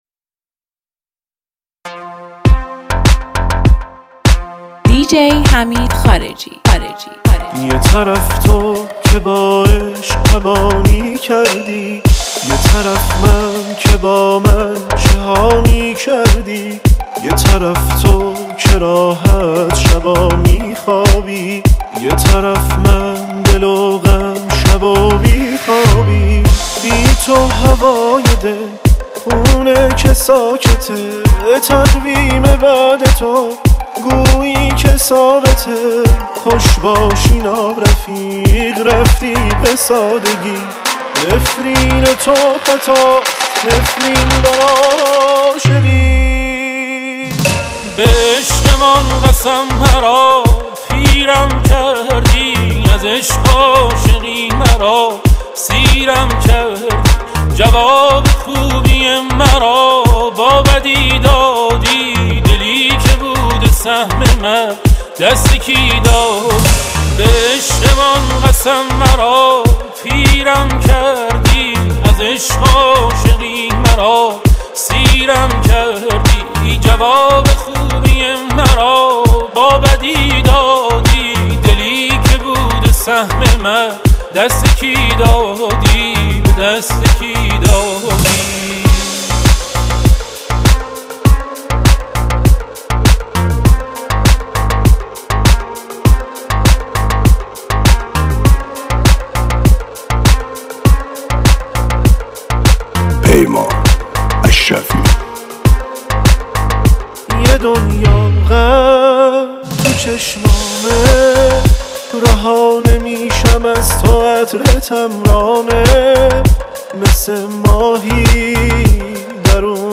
صدای مخملی و ریتم تند این ریمیکس، حال و هوات رو عوض می‌کنه.